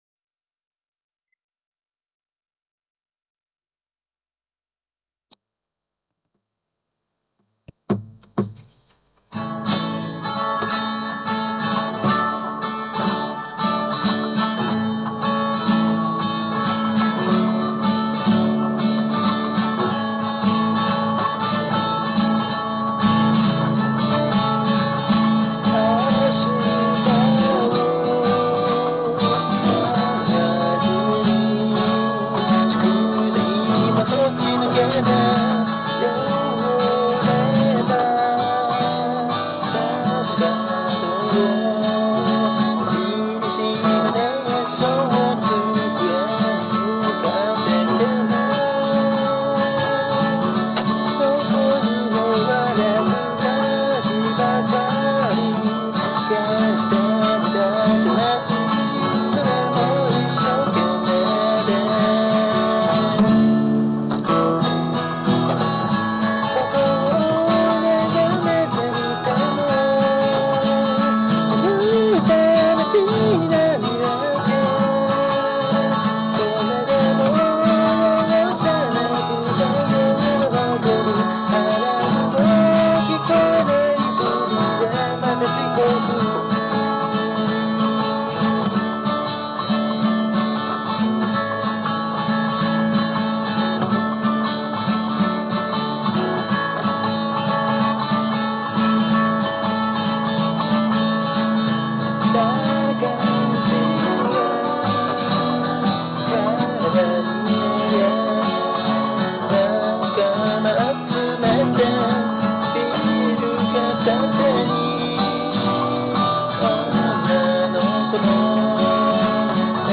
ぼんやりした想い出を思い出すあたりは、少しフラフラした不安定な曲調を意識して、最後は目覚めるような感覚にしたつもりです。
デモテープ音源